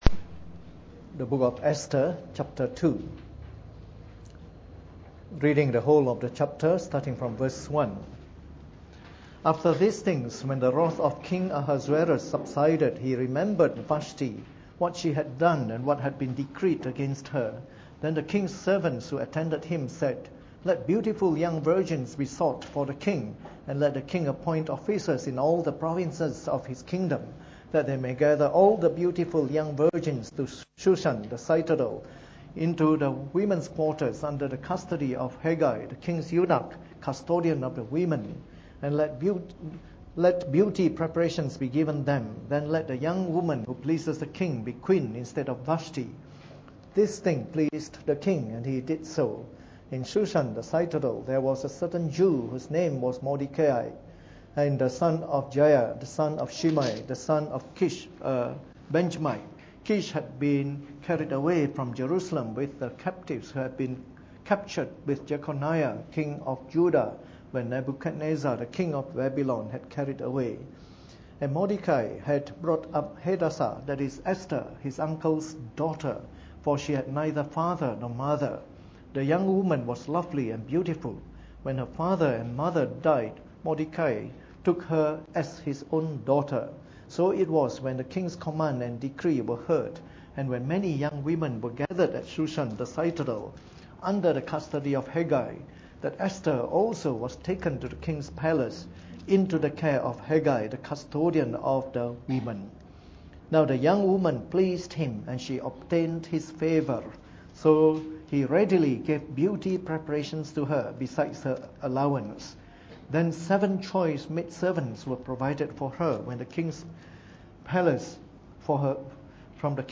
Preached on the 9th of October 2013 during the Bible Study, from our series of talks on the Book of Esther.